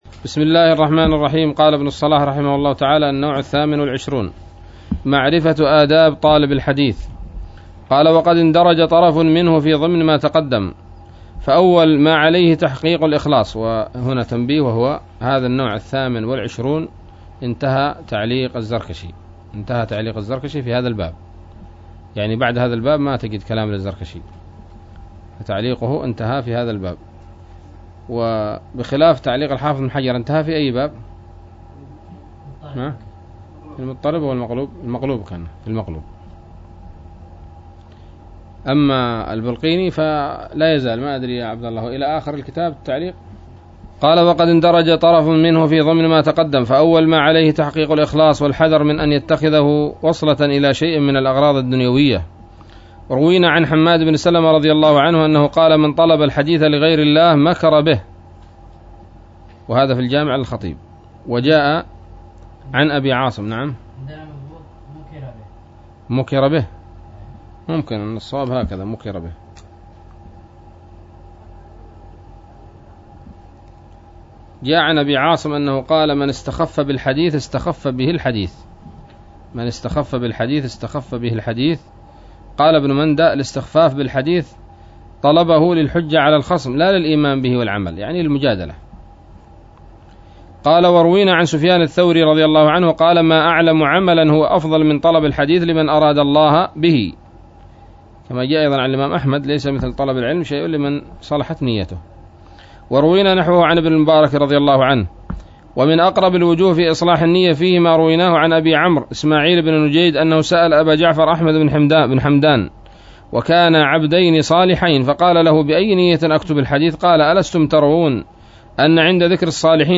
الدرس السادس والثمانون من مقدمة ابن الصلاح رحمه الله تعالى